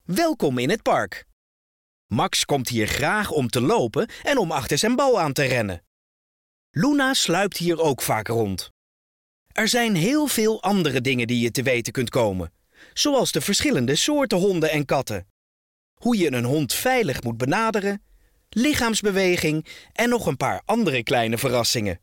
Park | Purina NL